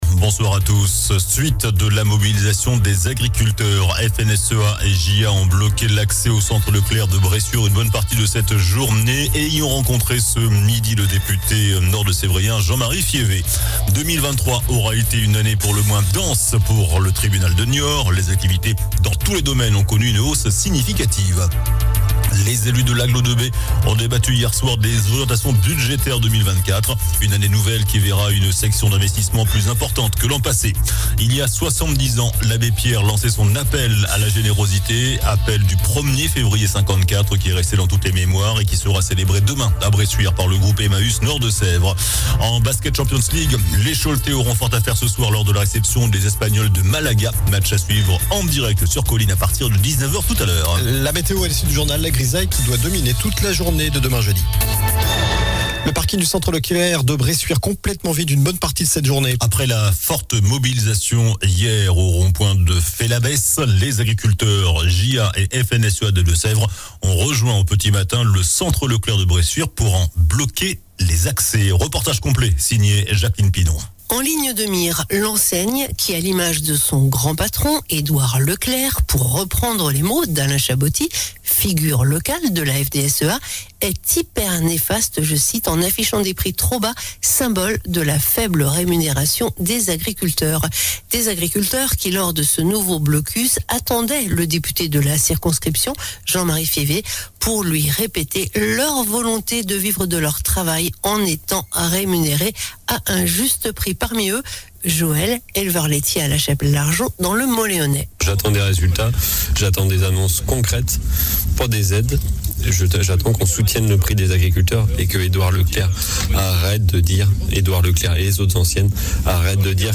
JOURNAL DU MERCREDI 31 JANVIER ( SOIR )